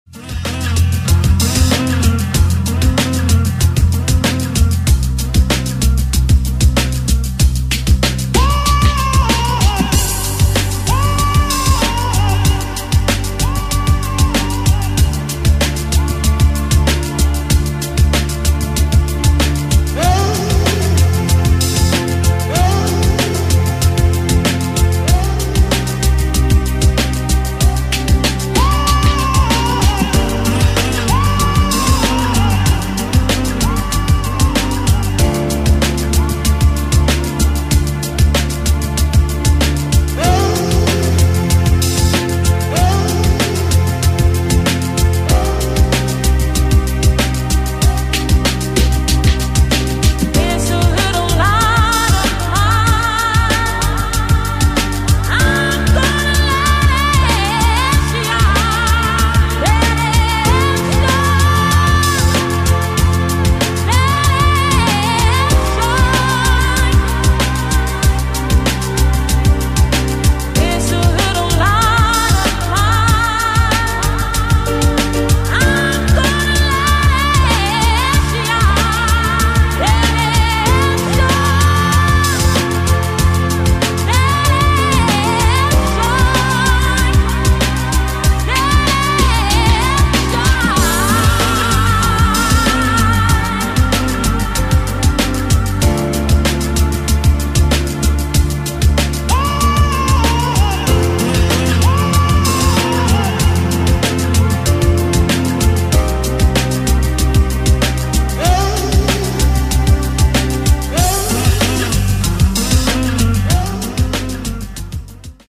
мужской голос
женский вокал
спокойные
красивая мелодия
chillout
электрогитара
расслабляющие
Lounge